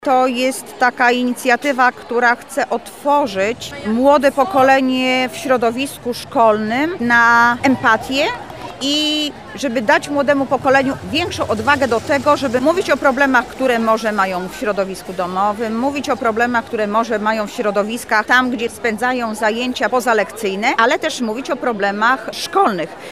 Anna Augustyniak-mówi Anna Augustyniak, zastępca prezydenta Lublina ds. Społecznych.